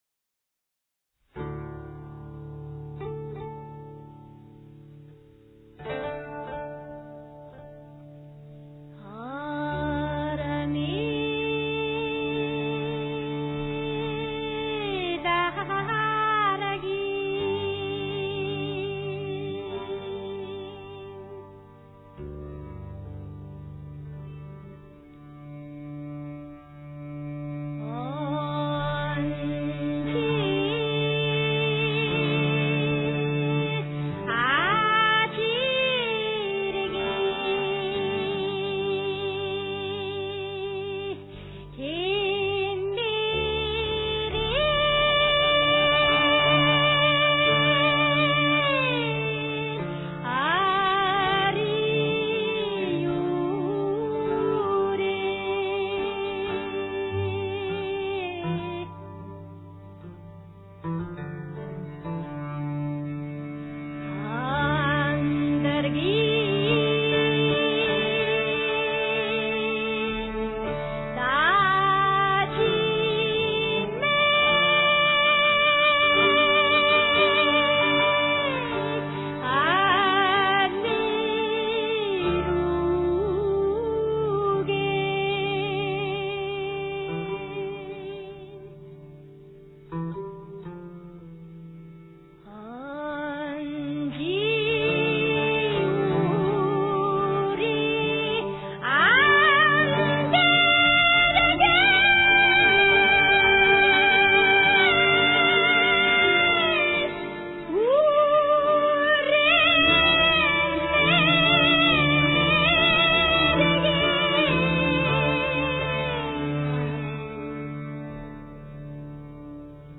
The Voice of the Mongolian Grasslands
Lo-Fi  mp3  format-